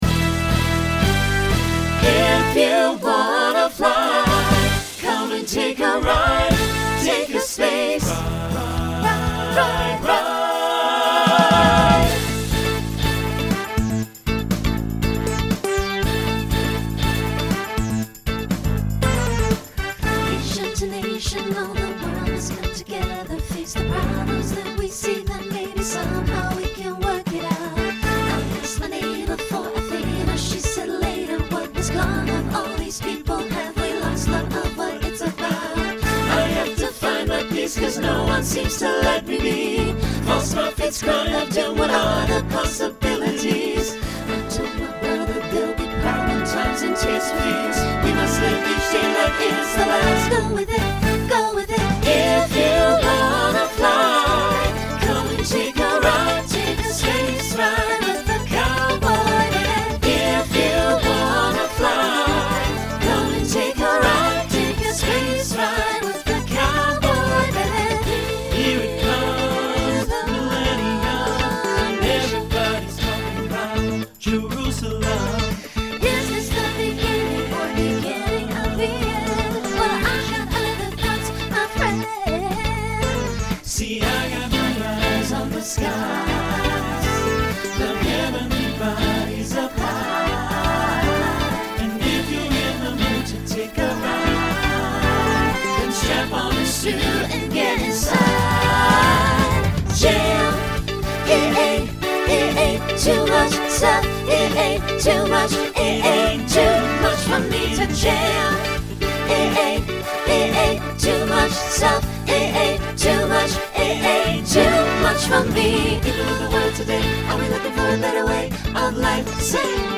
New SSA voicing for 2026.
Genre Pop/Dance Instrumental combo